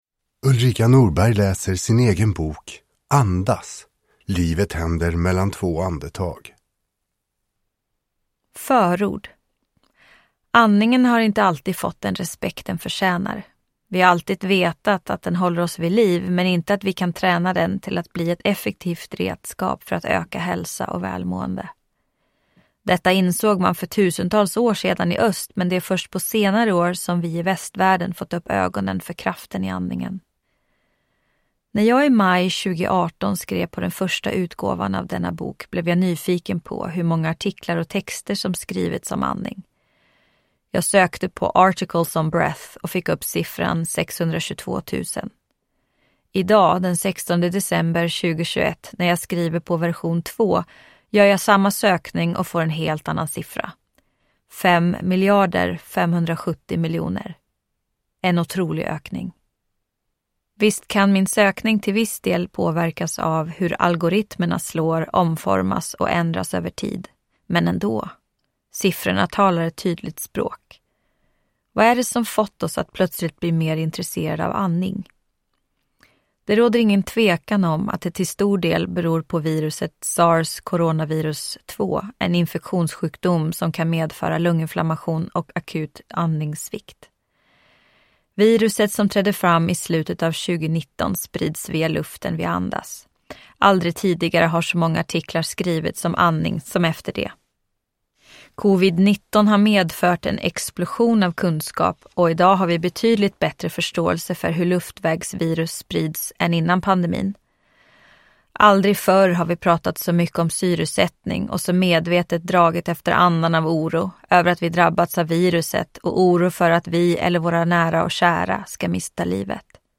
Andas : livet händer mellan två andetag – Ljudbok